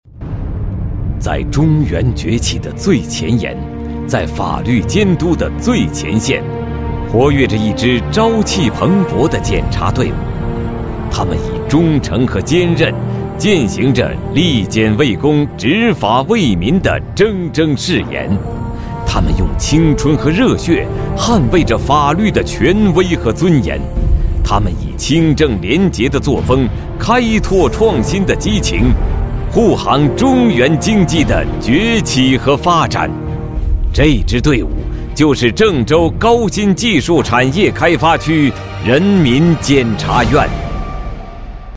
检察院男281号（磁性稳重
自然诉说 企业专题,人物专题,医疗专题,学校专题,产品解说,警示教育,规划总结配音
磁性稳重男音，声线偏中年。